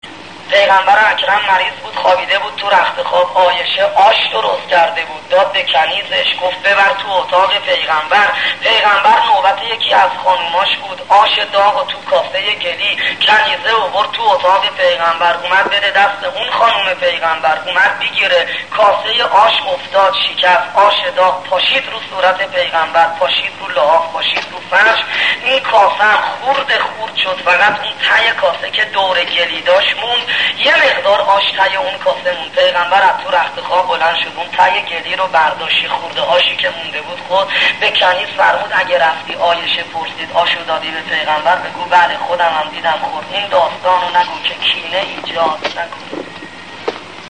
داستانهای شنیدنی در مورد پیامبر اکرم صلی الله علیه و آله در بیانات استاد آیت الله شهید سید عبدالحسین دستغیب رحمه الله